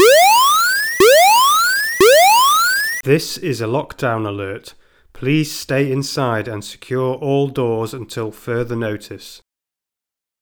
Commercial Audio are able to design and supply audio system components which can replace or work alongside your existing communication systems to provide site wide initiation of a lockdown in response to a threat as well as voice announcements to confirm the status of the emergency to all occupants.
Lockdown Trigger Message